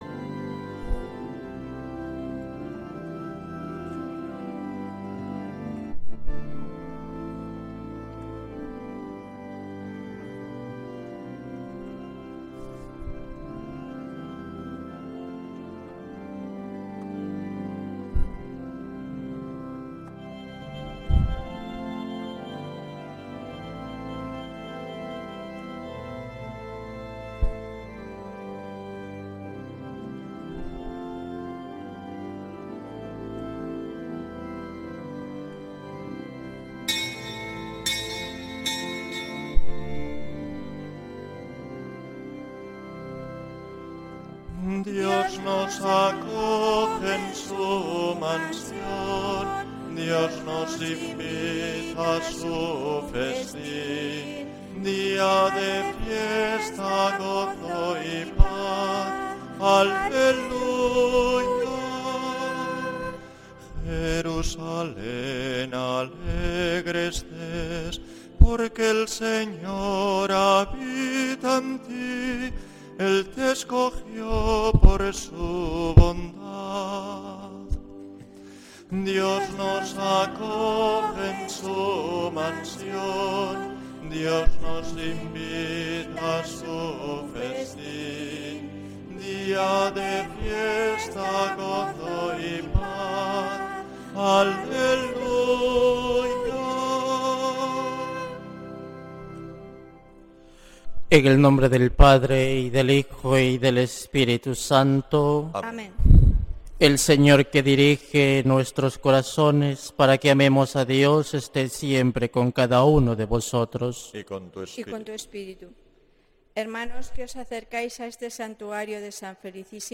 Santa Misa desde San Felicísimo en Deusto, domingo 3 de agosto de 2025